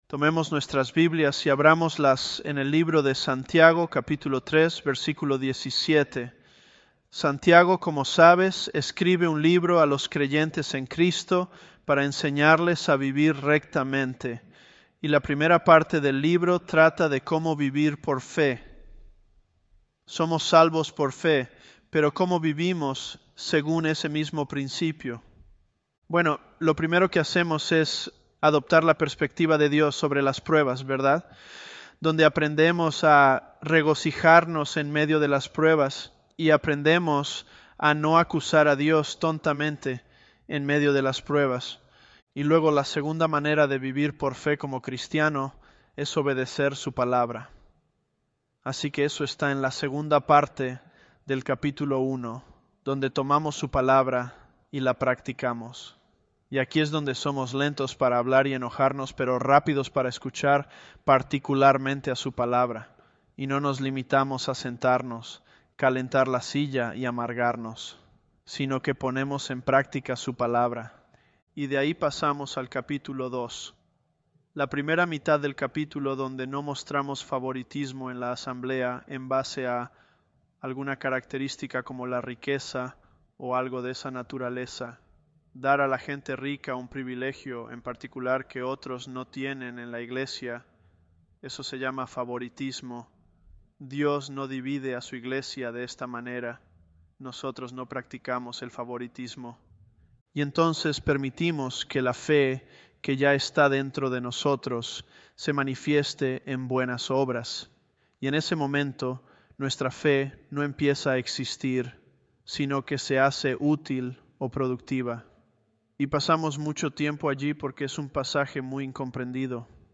ElevenLabs_James019.mp3